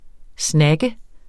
Udtale [ ˈsnagə ]